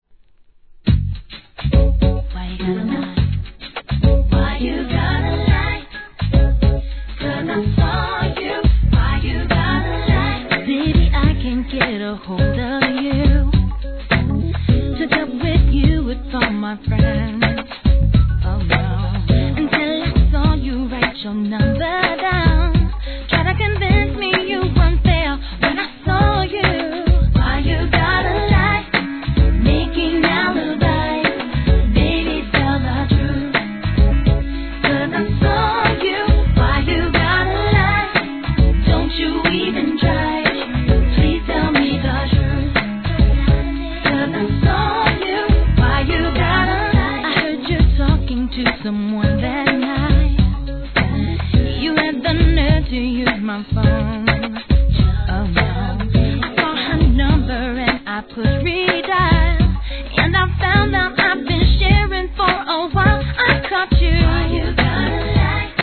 HIP HOP/R&B
B/WにはREGGAE DANCEHALL REMIX収録です。